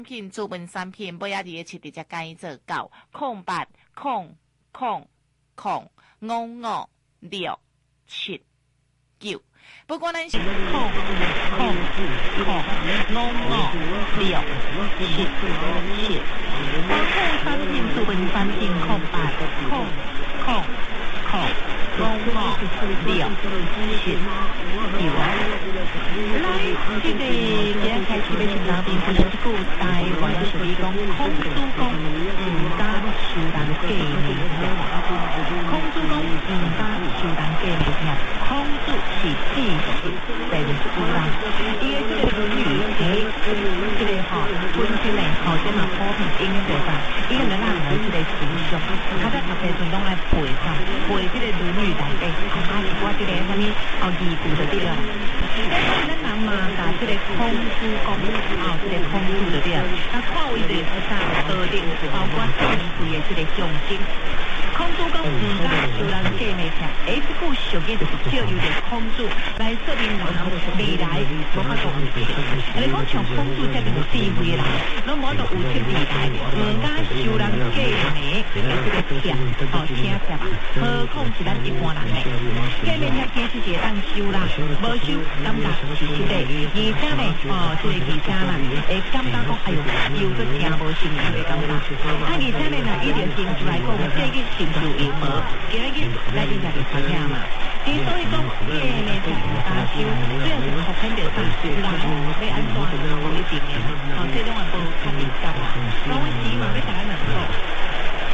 Alussa pätkä aseman podcastistä joka jatkuu omalla AM-tallenteella.